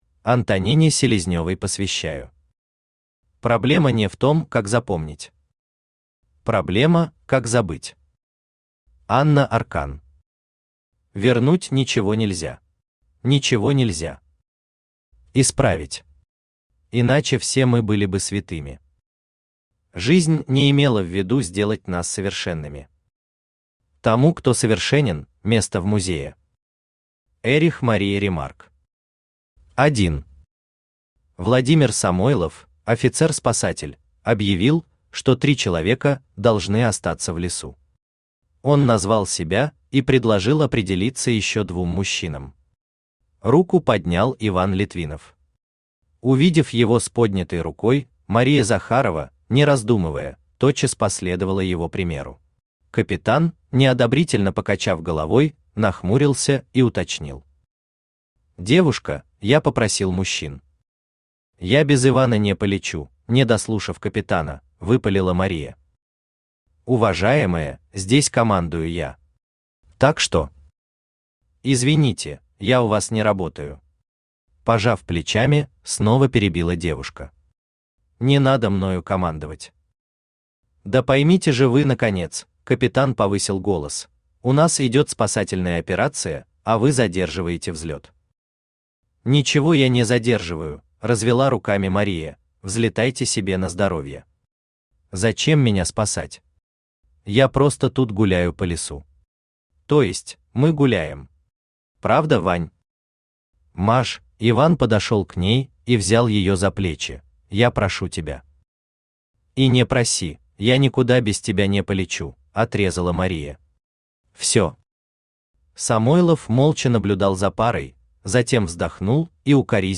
Аудиокнига Последняя фотография | Библиотека аудиокниг
Aудиокнига Последняя фотография Автор Михаил Самарский Читает аудиокнигу Авточтец ЛитРес.